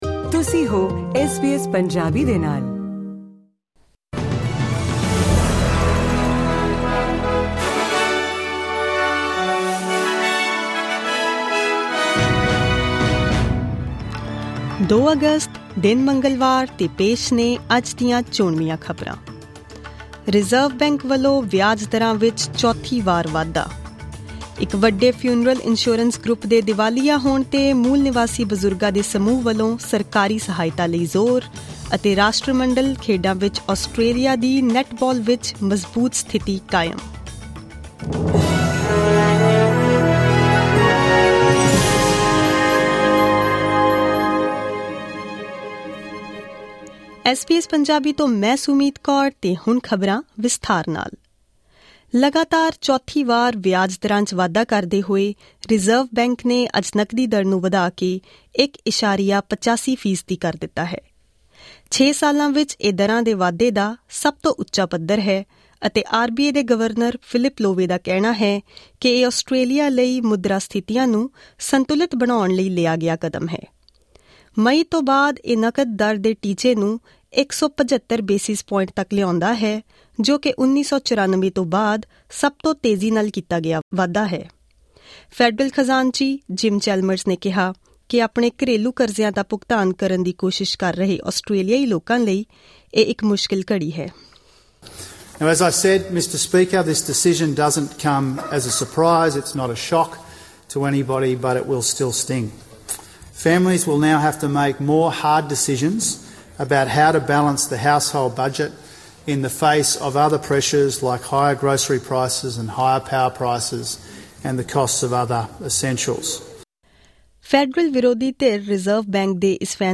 Click on the audio button to listen to the news bulletin in Punjabi.